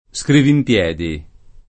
SkrivimpL$di] s. m. — meno com. scrivinpiedi [id.]: non c’era che lo scritturale allo scrivinpiedi [